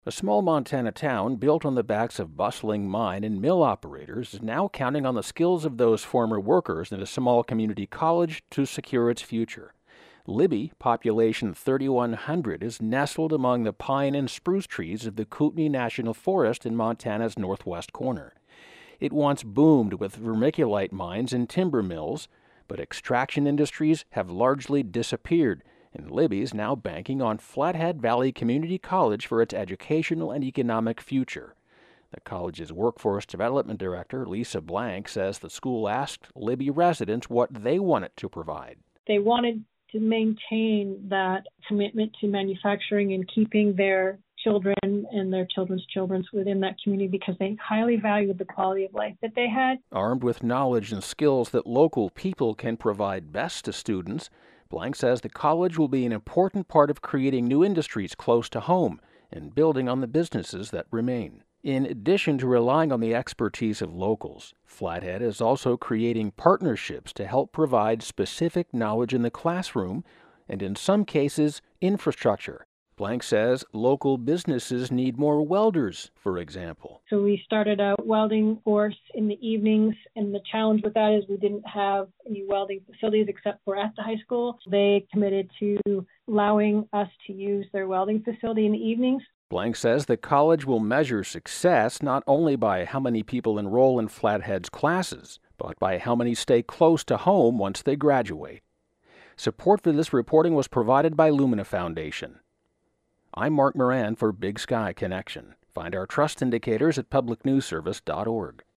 (Pronouncer: Kootenai, "KOOT-nee.")